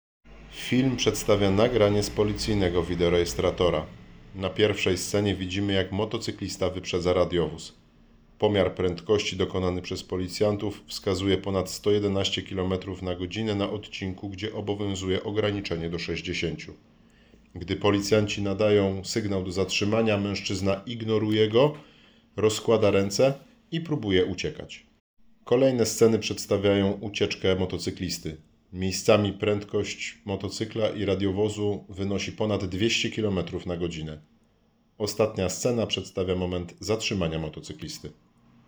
Nagranie audio Audiodeskrypcja_do_filmu.mp3